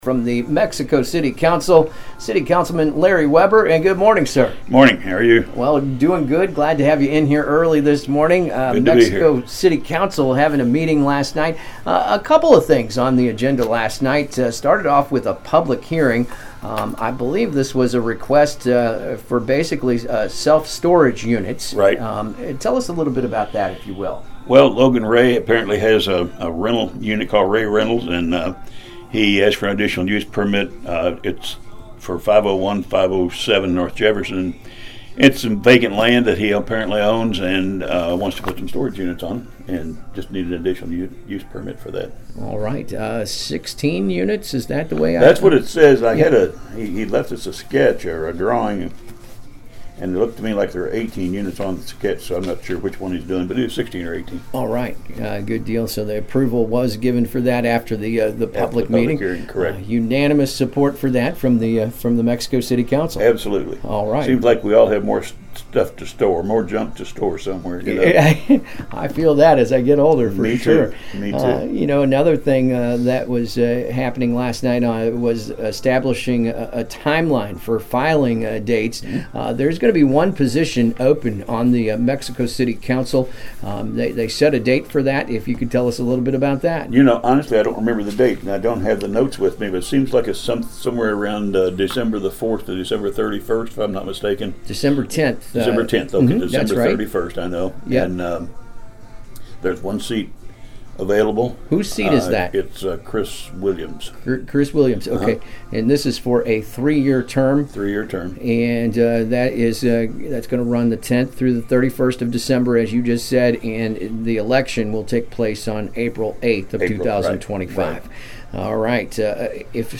Mexico City Councilman Larry Webber Joins AM 1340 KXEO Am I Awake Morning Show